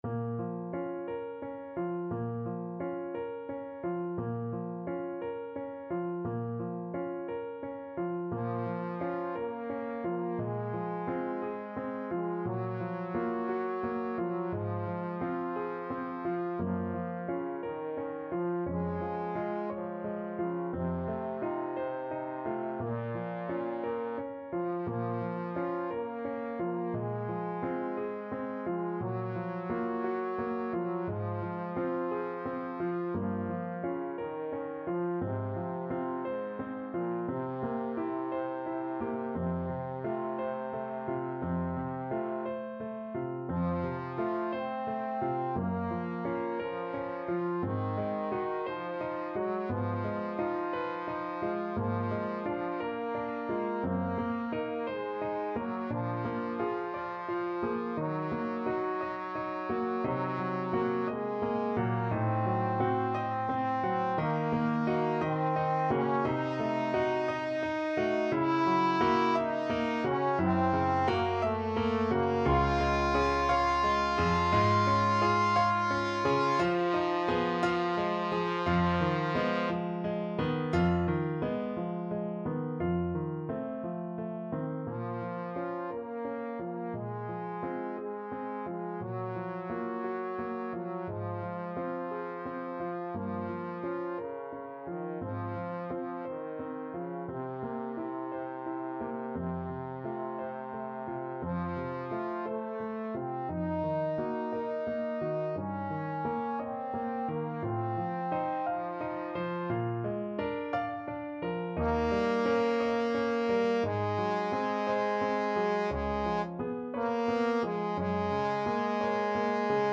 Trombone version